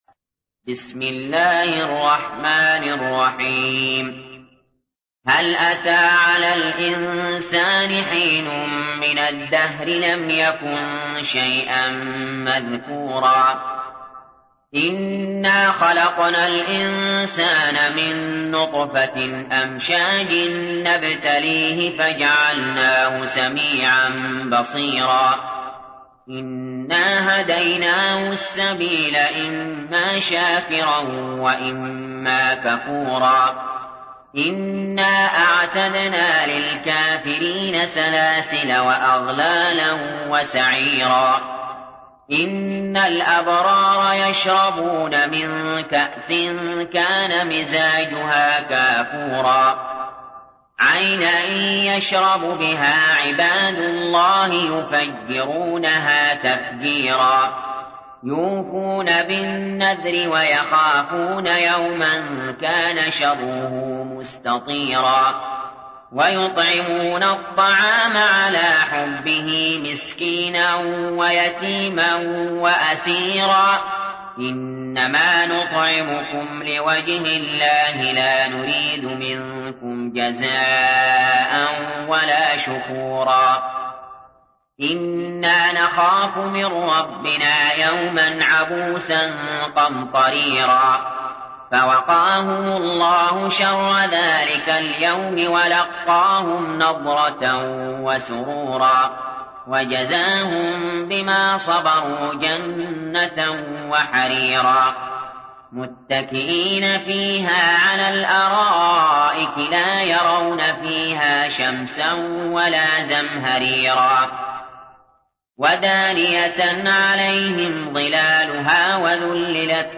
تحميل : 76. سورة الإنسان / القارئ علي جابر / القرآن الكريم / موقع يا حسين